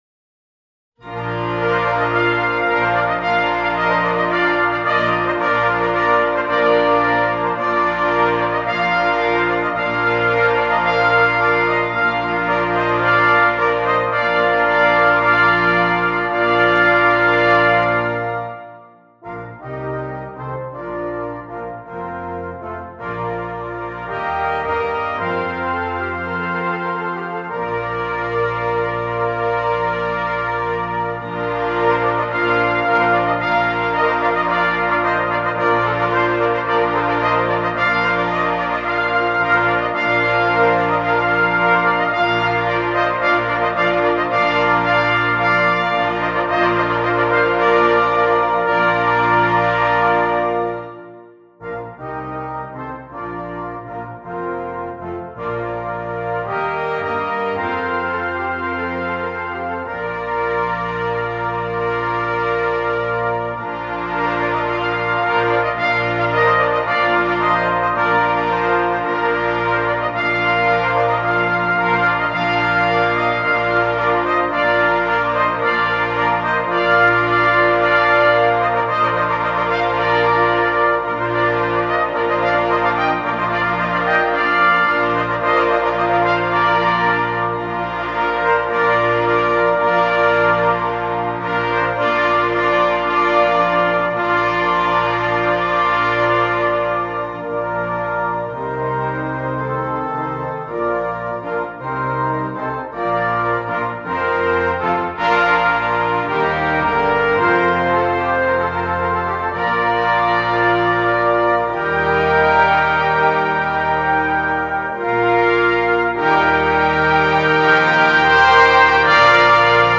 Brass Choir (5.4.5.0.2.organ)